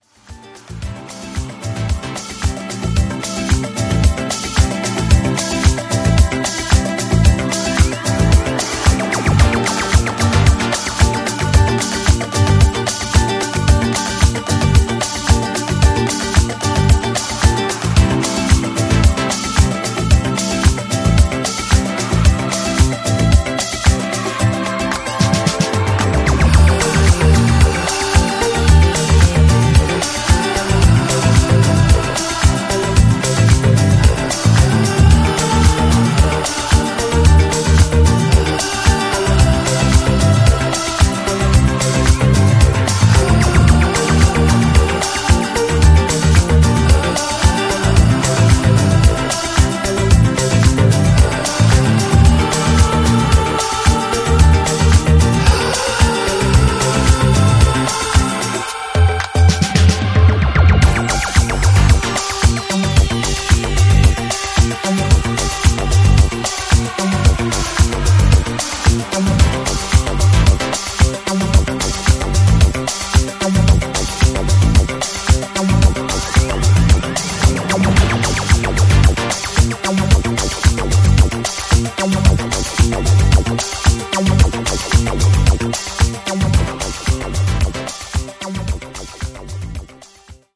バレアリックやアシッド等の要素をセンス良くブレンドさせた、